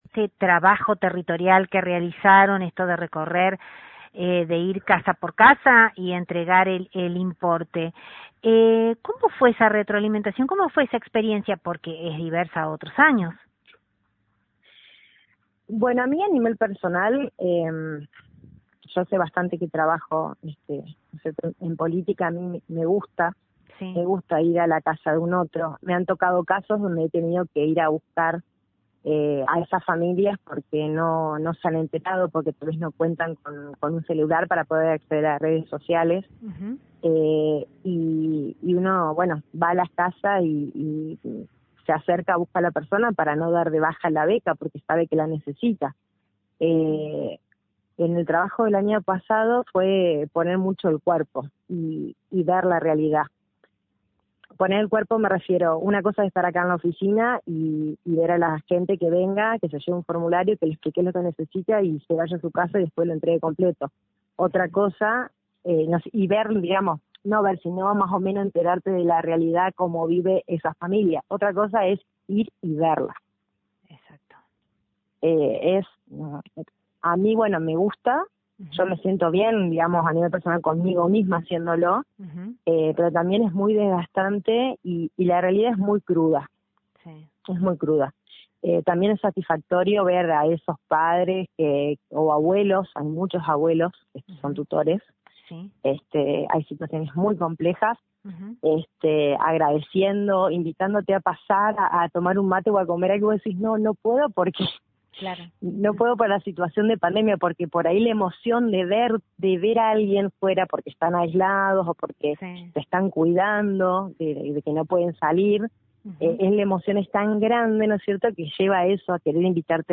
en diálogo con nuestro medio